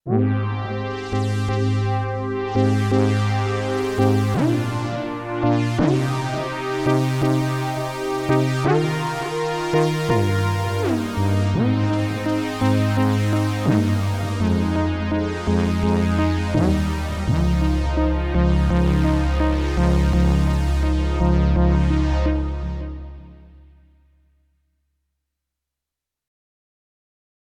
Charleston Sync Pad
Charleston-Sync-Pad.wav